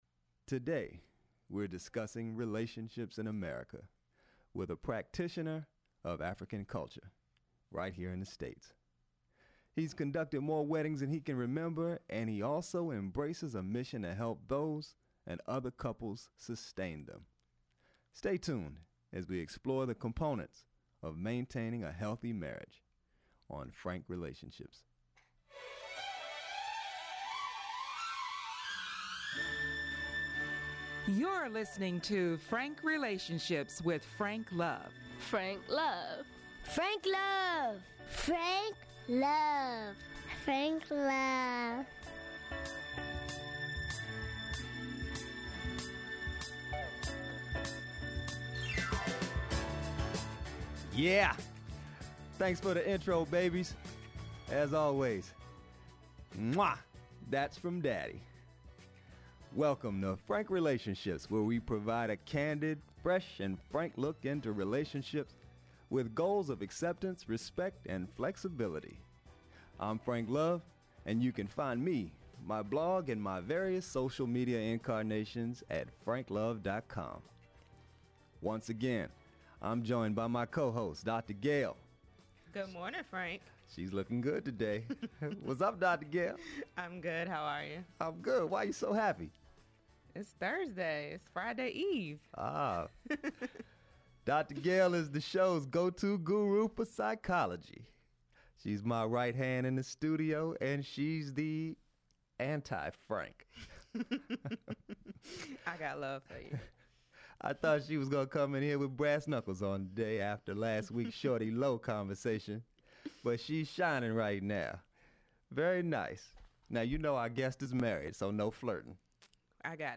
Podcast Episode: Today we are discussing relationships in America with a practitioner of African culture right here in the states.